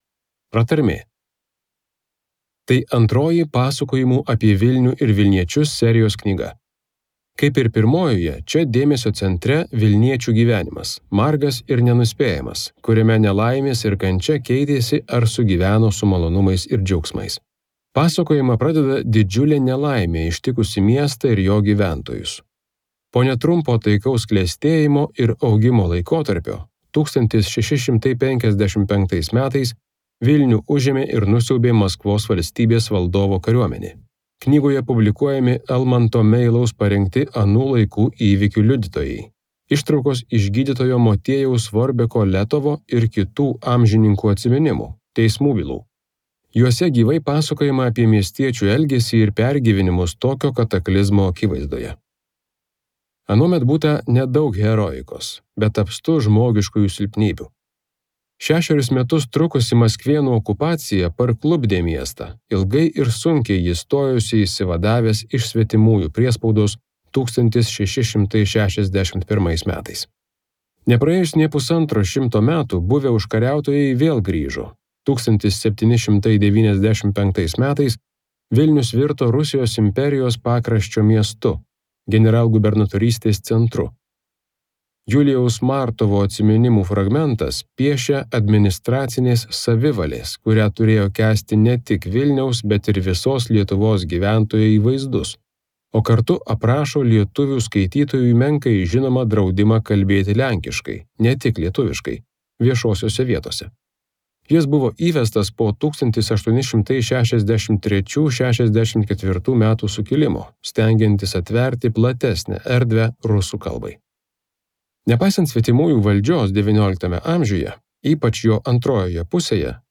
Audio Pasakojimai apie Vilnių ir vilniečius II